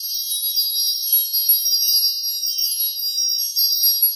magic_sparkle_gem_loop_01.wav